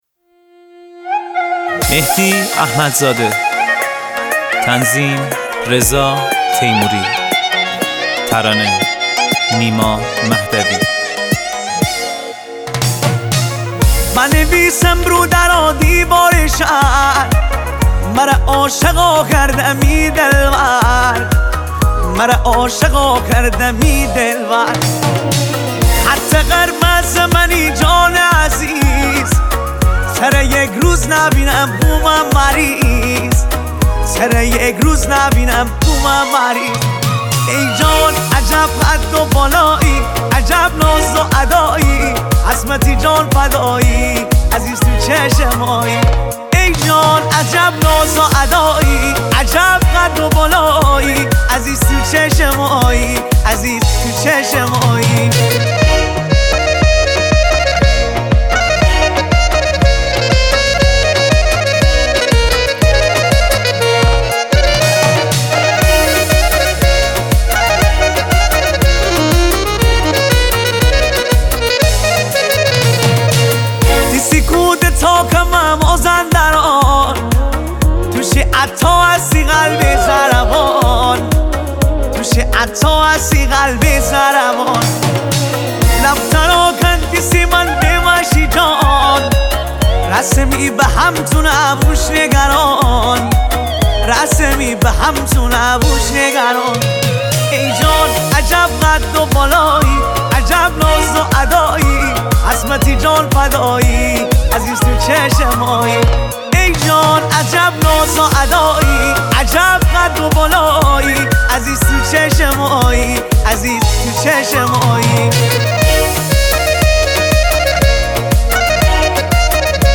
موزیک لاتی
آهنگ مازندرانی